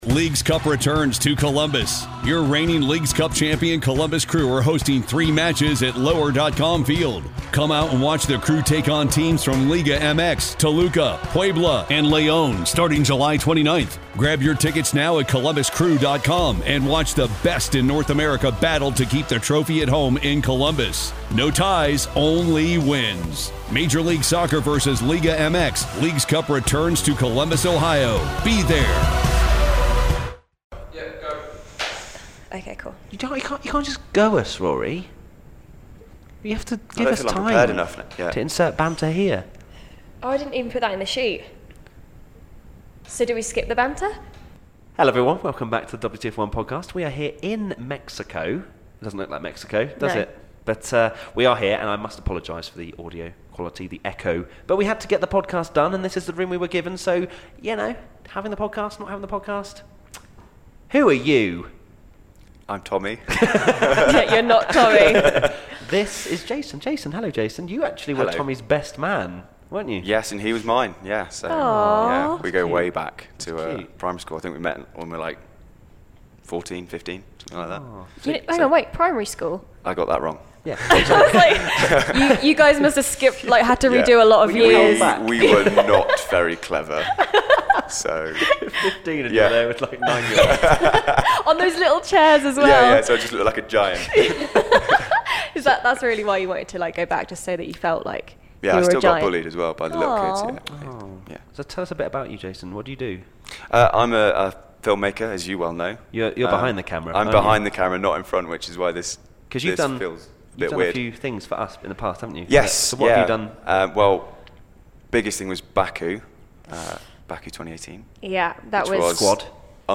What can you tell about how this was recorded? Apologising for echo in Mexico